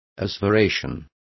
Also find out how aseveracion is pronounced correctly.